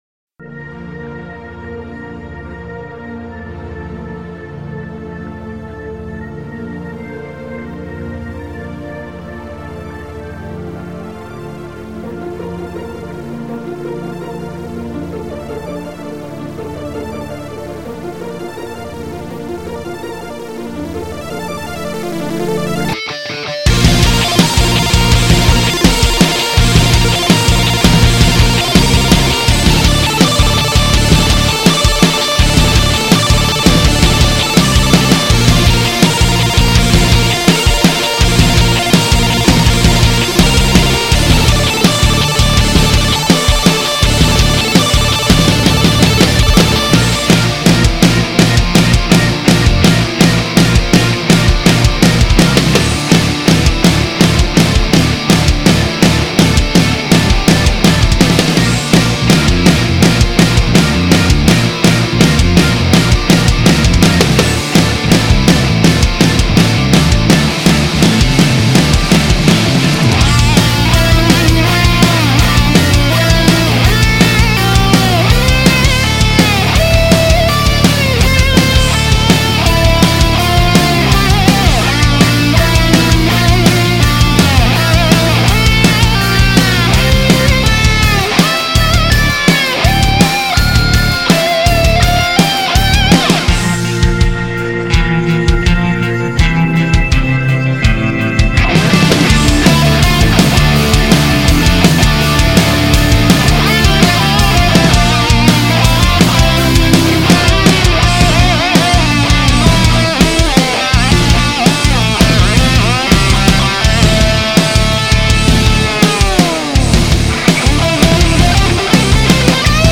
video game remix